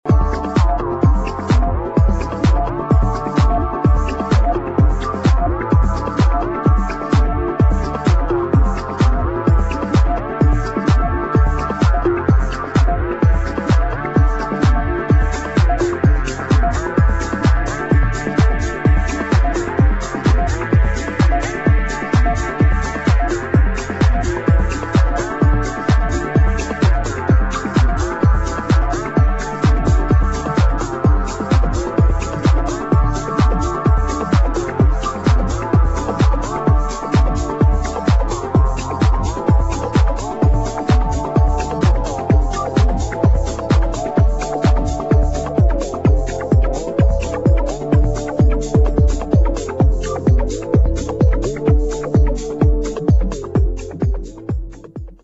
[ TECHNO | TECH HOUSE | MINIMAL ]